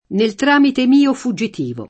nel tr#mite m&o fuJJit&vo] (D’Annunzio) — inv., di regola, se usato per ellissi come prep.: inoltrare la domanda tramite gli uffici competenti